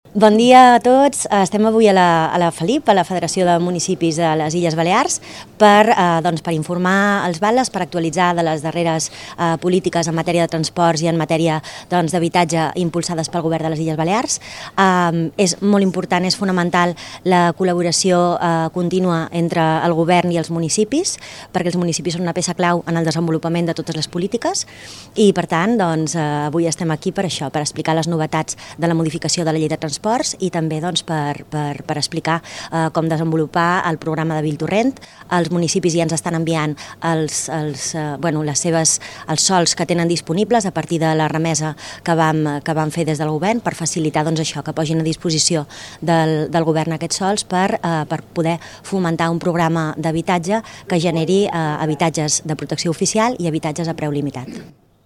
Declaracions de la consellera d'Habitatge, Territori i Mobilitat, Marta Vidal